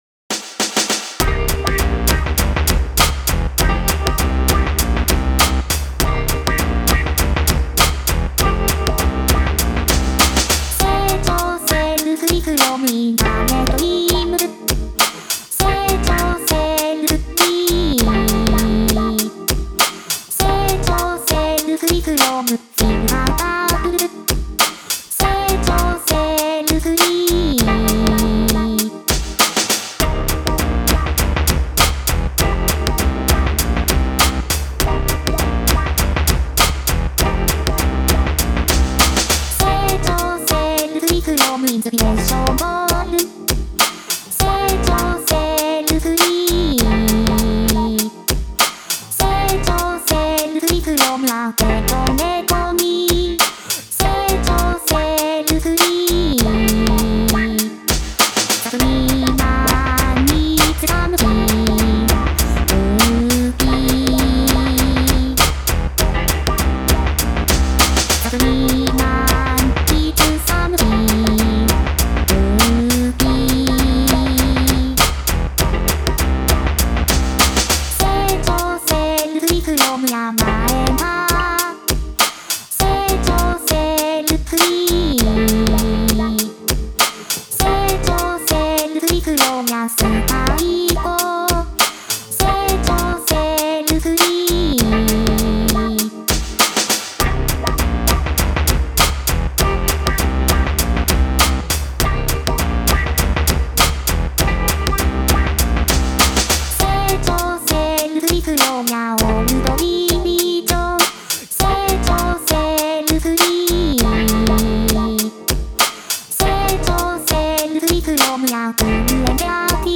ベースラインが好き